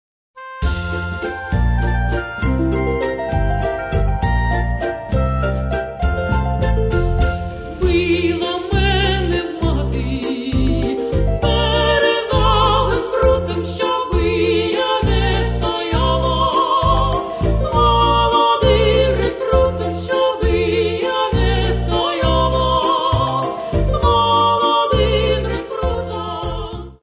музика: народна пісня